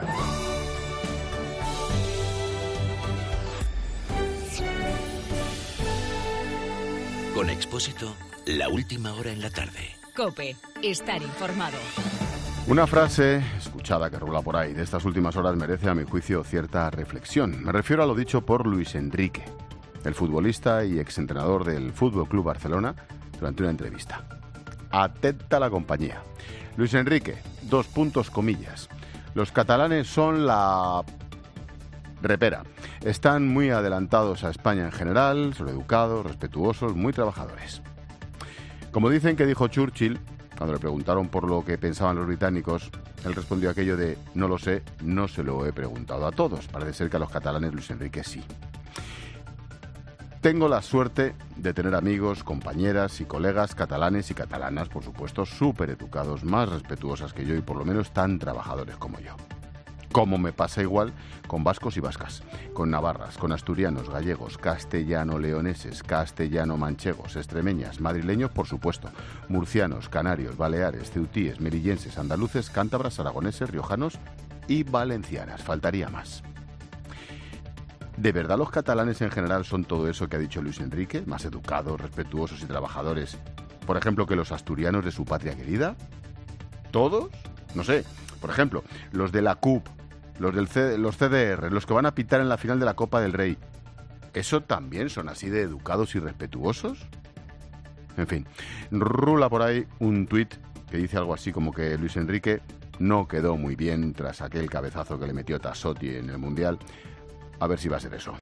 Monólogo de Expósito
El comentario de Ángel Expósito sobre las palabras de Luis Enrique sobre los catalanes.